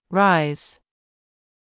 rise /ráiz/ 「上がる」＜他動詞＞